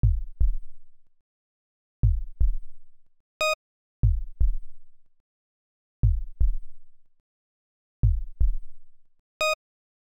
Heartbeat Hospital -
I really love using the trope of a heartbeat to make things feel more dangerous, and decided to make one that had a beep as if it were a hospital machine that is monitoring you. I put the beeps in with different space between them, and if I were to expand this I would add more beeps with varying spaces. I feel it adds to the anxiety of the entire experience, as if you were actually in a hospital yourself!
hospital.wav